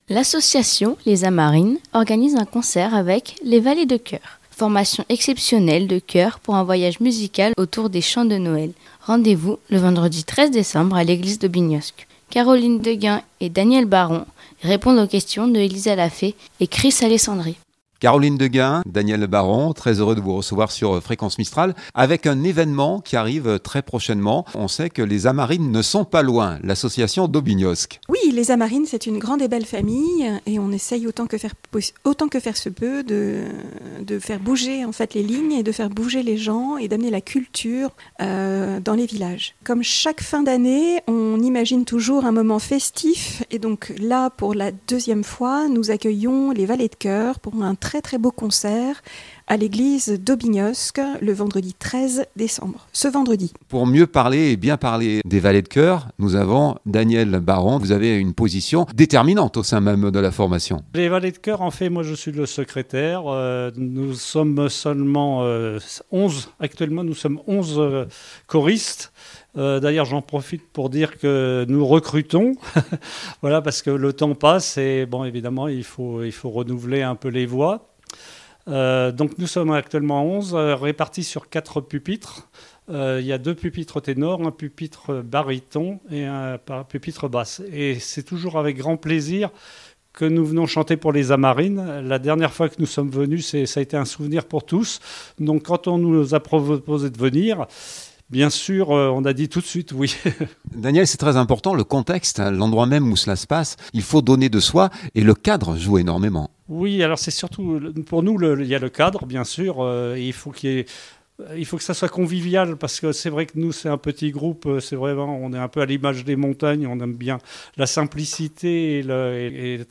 LES AMARINES EMISSION COMPLETTE.mp3 (18.08 Mo)